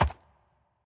Minecraft Version Minecraft Version snapshot Latest Release | Latest Snapshot snapshot / assets / minecraft / sounds / mob / horse / skeleton / water / soft1.ogg Compare With Compare With Latest Release | Latest Snapshot